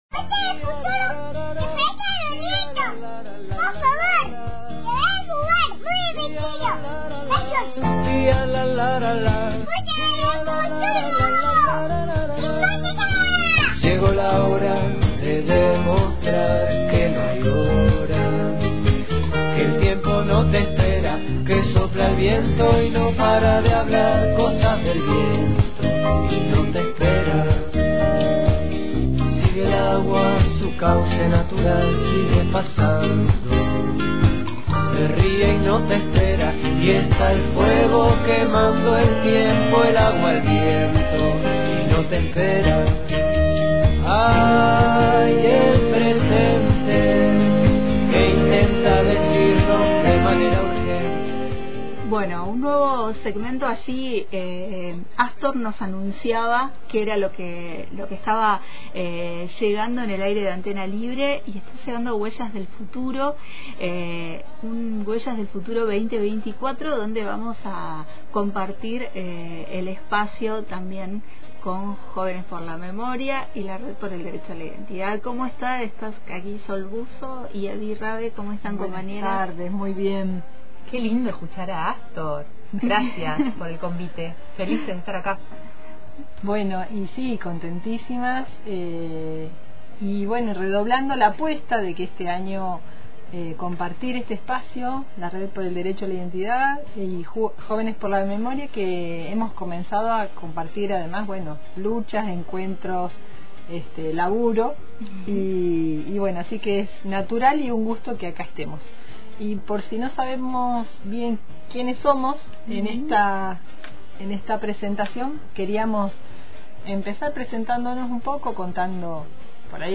También ompartimos nuestra preocupación ante la situación de amenaza de desalojo a la biblioteca de la Estación y convocamos a la reunión multisectorial en la universidad de Río Negro. Como cierre, escuchamos un audio de nuestras compañeras lesbianas activistas por la memoria, la verdad y la justicia, en el día de la visibilidad lésbica, e invitamos a las actividades a realizarse en Fiske Menuco.